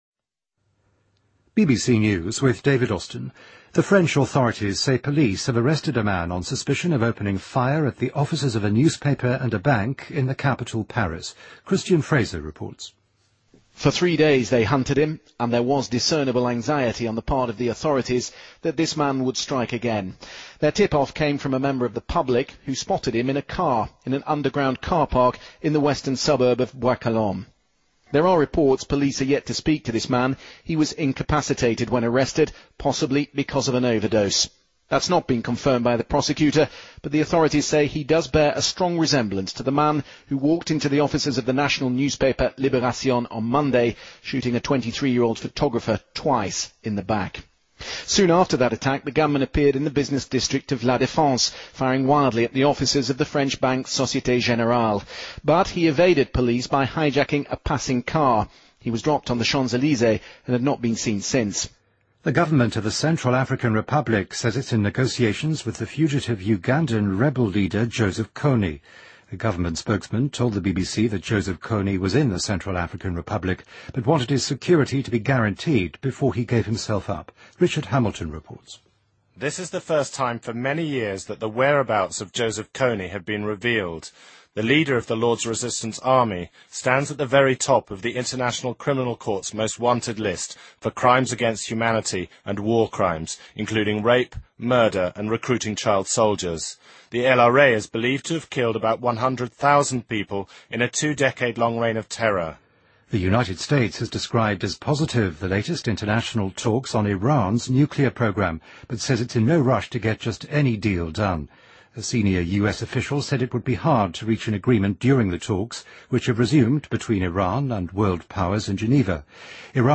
BBC news,拉脱维亚首都里加一家大型超市屋顶坍塌